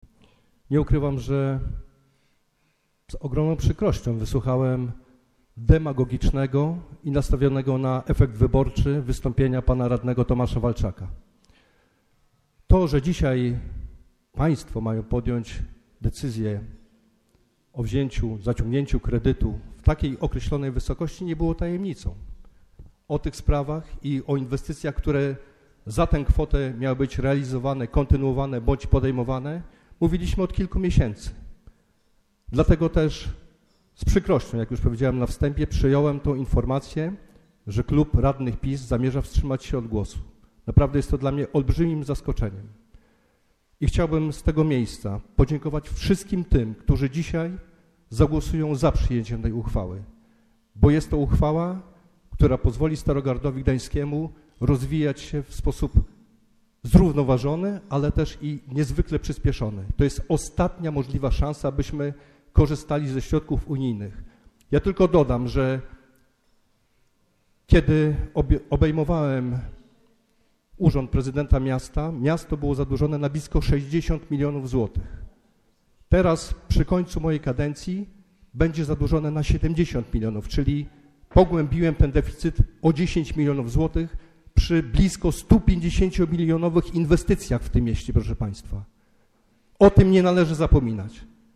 Bardzo zaskakujący przebieg miała wczorajsza sesja Rady Miasta.